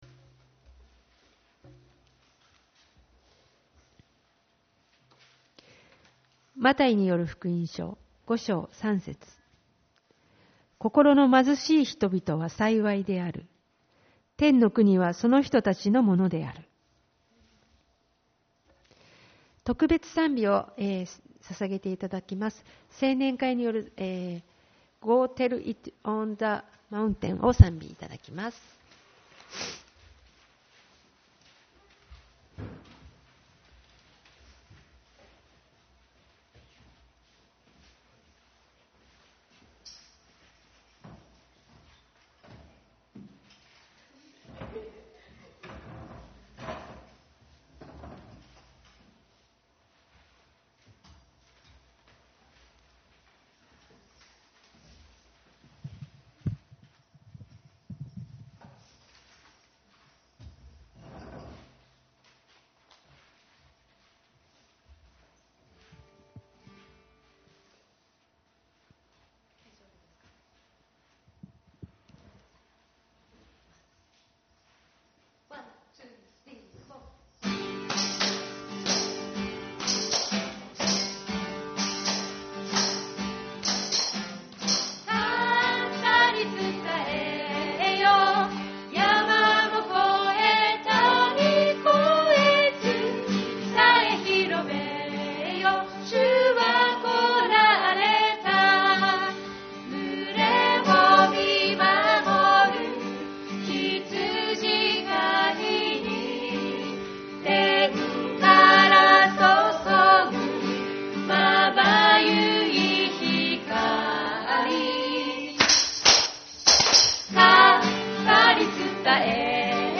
主日礼拝 「新しいとき｣